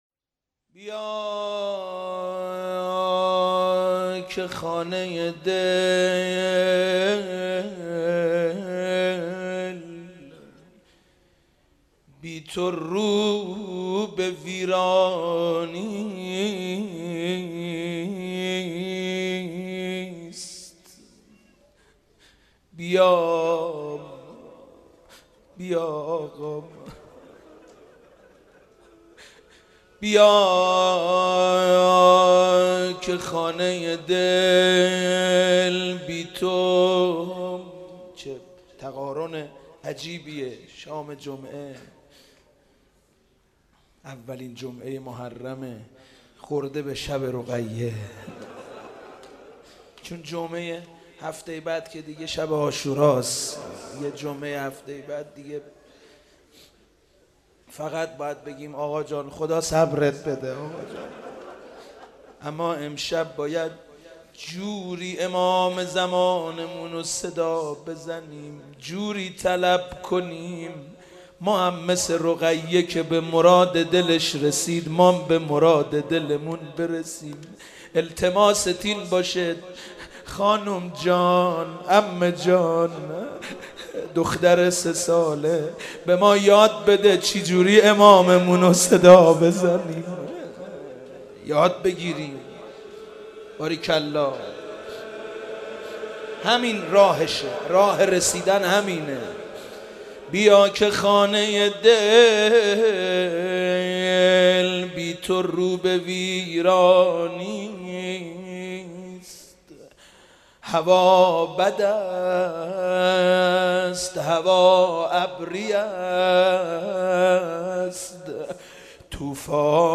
شب سوم محرم94_روضه_ بیا که خانه ی دل بی تو رو به ویرانی است